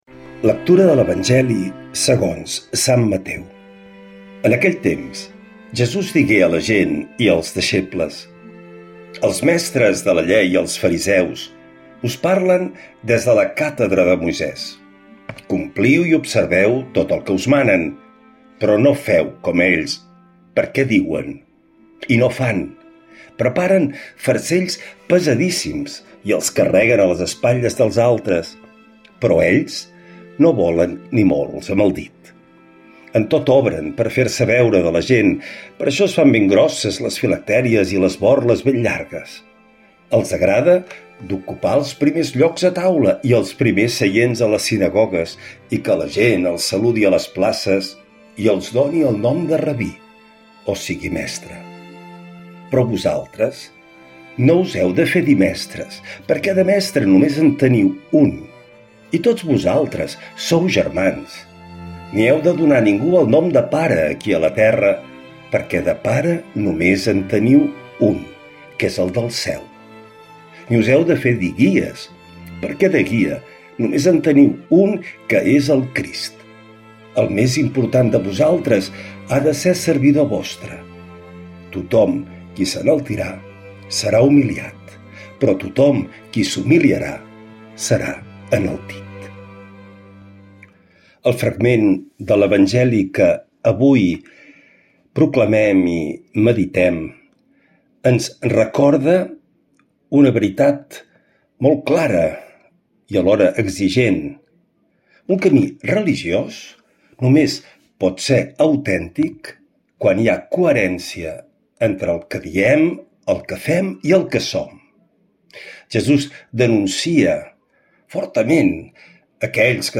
L’Evangeli i el comentari de dissabte 23 d’agost del 2025.
Lectura de l’Evangeli segons Sant Mateu